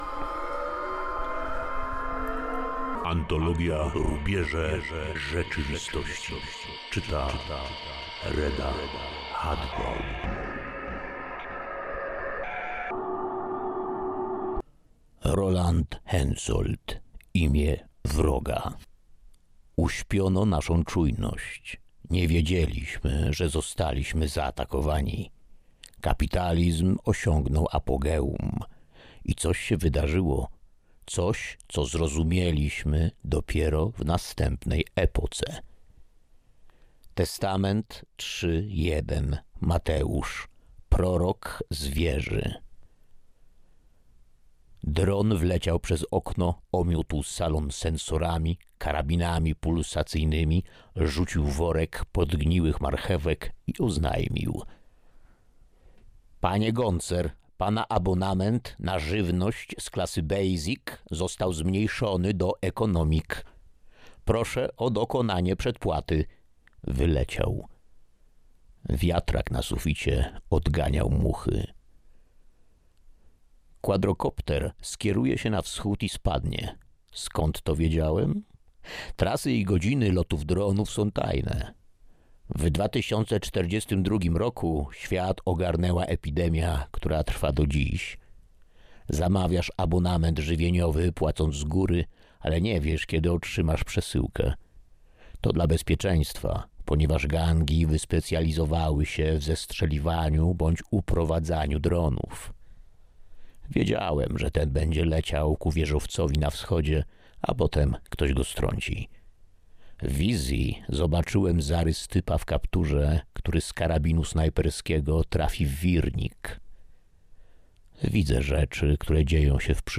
Na Bibliotekarium prezentujemy opowiadanie Roland Hensoldt Imię wroga, autorstwa Rolanda Hensoldta.